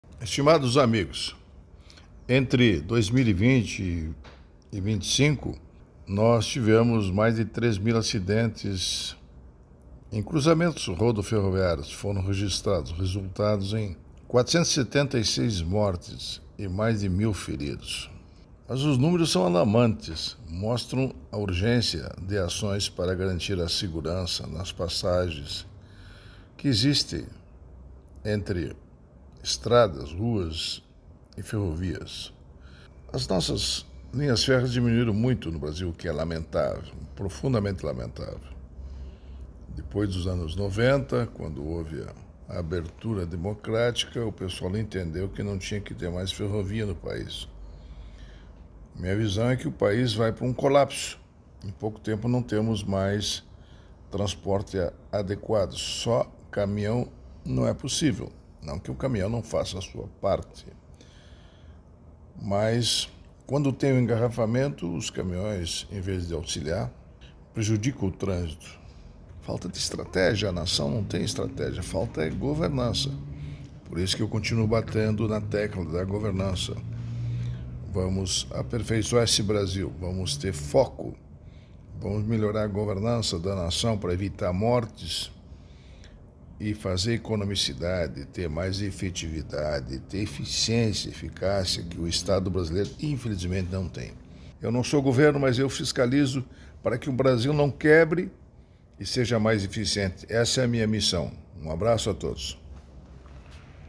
Augusto Nardes é ministro do Tribunal de Contas da União.
02-Ministro-prog-radio-numeros-de-acidentes-em-cruzamentos-mostram-urgencia-de-acoes.mp3